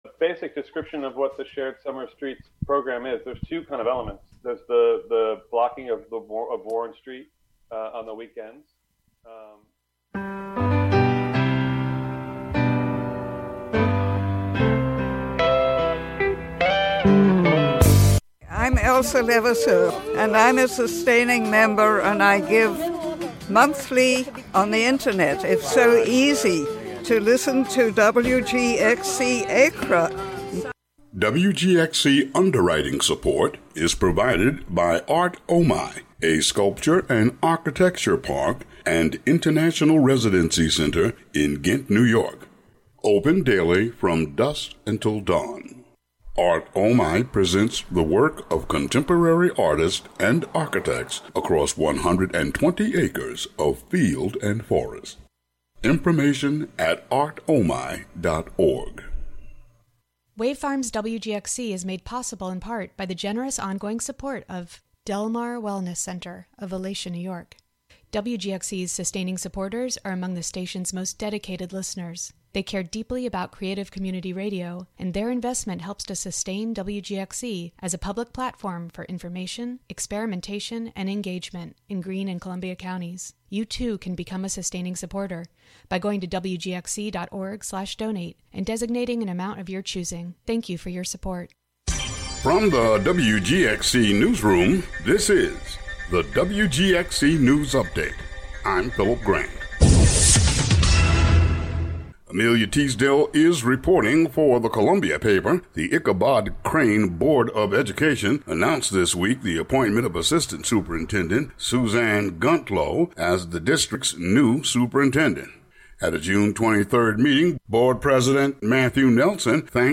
Hear the June 24 Village of Catskill meeting debate about painting a "Black Lives Matter" mural on Main Street, where the board does not take any action.